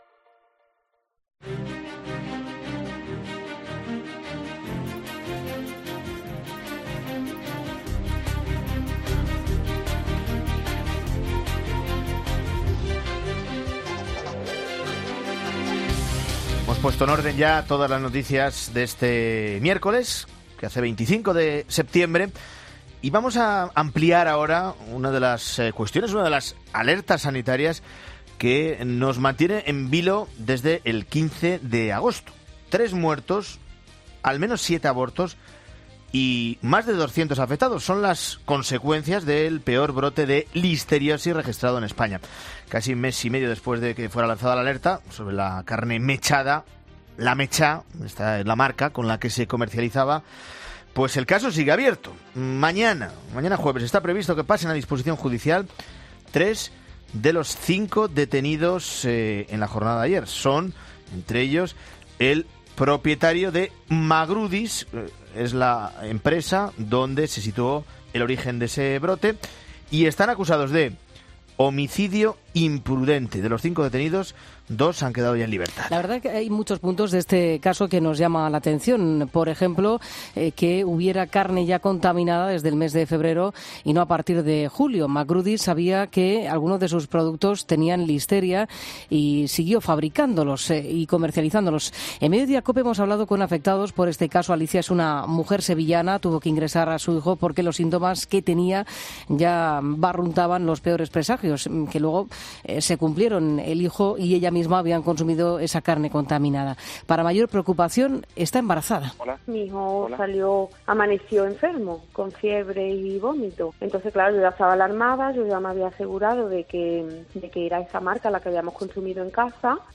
En Mediodía COPE hemos hablado con afectados por el caso.